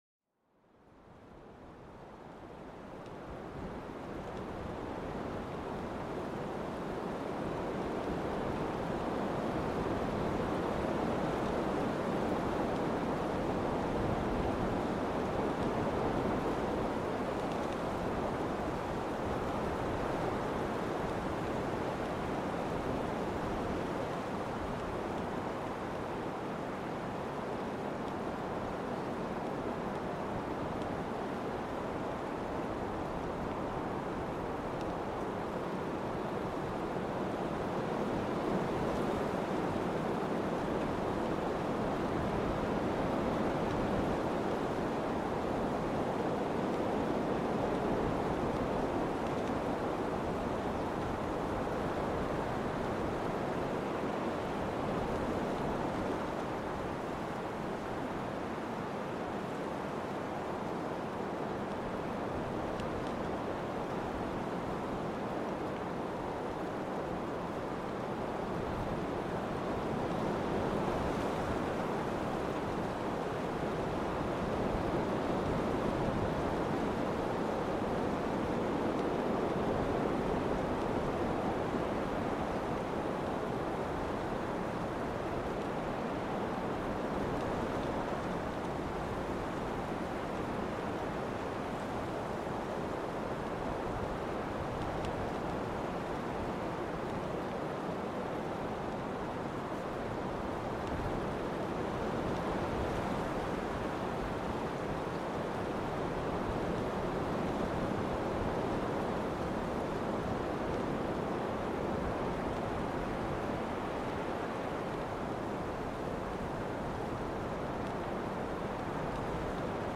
Descubre el poder vivificante del viento en la naturaleza, donde cada ráfaga cuenta una historia diferente, este episodio te invita a sentir la caricia del viento, un elemento esencial de nuestro entorno natural, déjate llevar por el canto del viento, un sonido que despierta los sentidos y trae una sensación de libertad y frescura.Este podcast es una experiencia de audio inmersiva que sumerge a los oyentes en los maravillosos sonidos de la naturaleza.